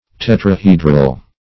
Tetrahedral \Tet`ra*he"dral\, a. [See Tetrahedron.]